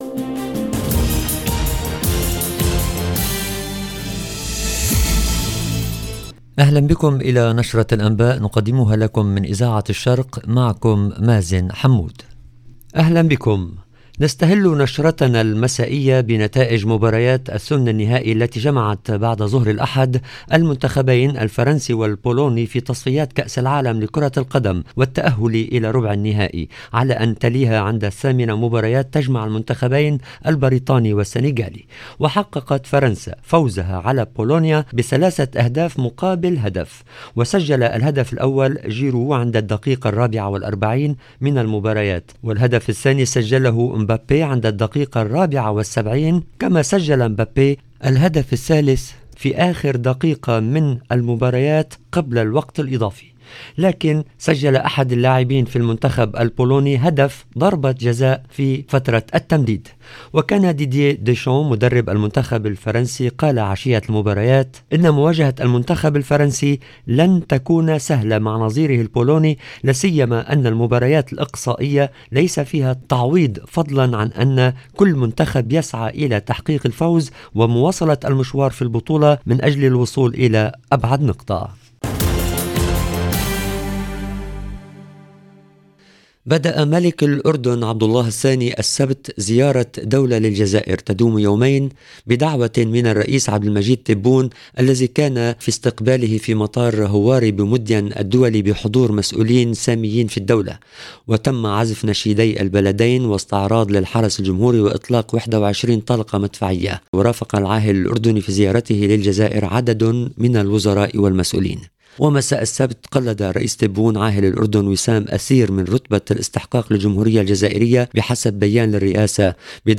LE JOURNAL DU SOIR EN LANGUE ARABE DU 4/12/22